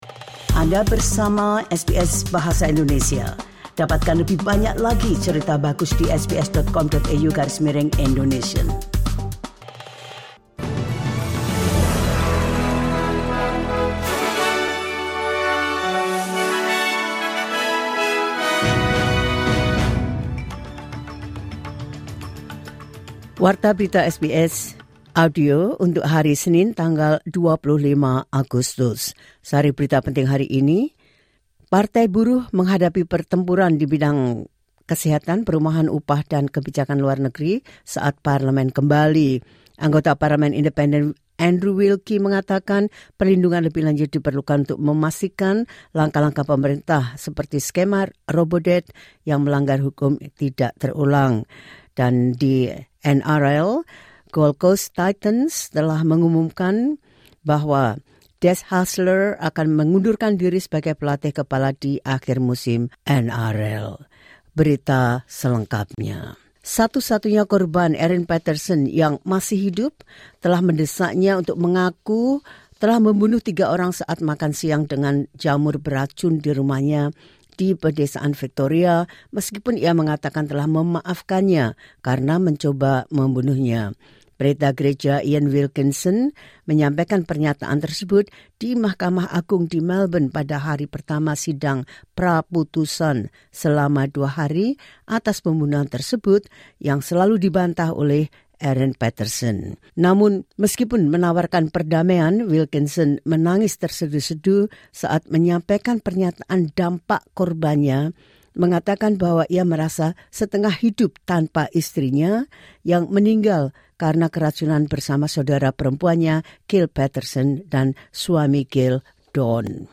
The latest news SBS Audio Indonesian Program – 25 August 2025.